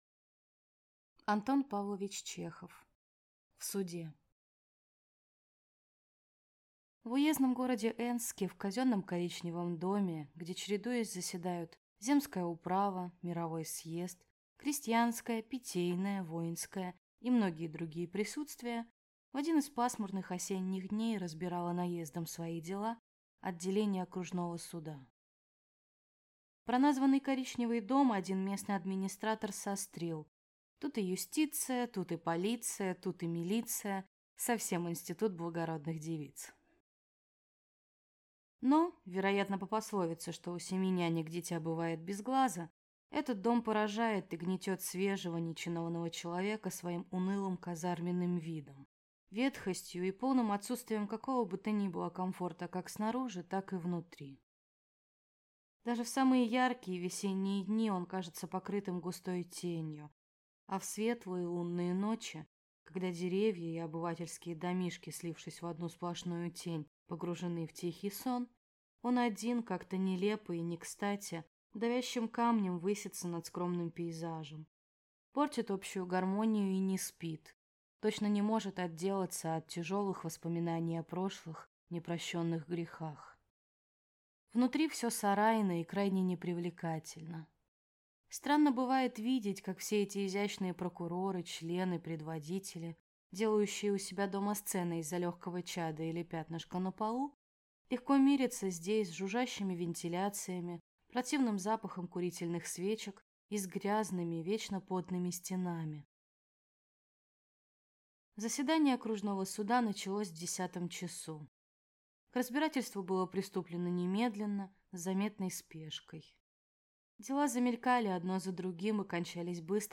Аудиокнига В суде | Библиотека аудиокниг